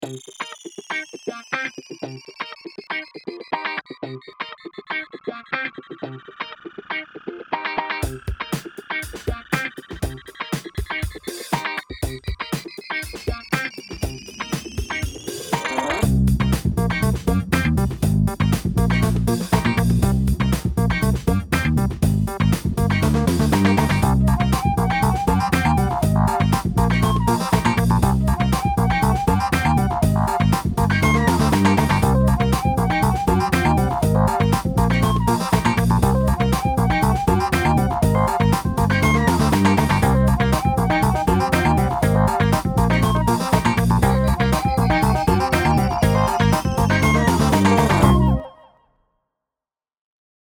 And it's a song that acts like stairs, 'cause when you listen to it, the longer the song is, the more it builds, and the more instruments and sounds get added to it.
Here's an example of a Stair Song if you're having a hard time imagining what I mean.
It's a song that just keeps building and building until the end.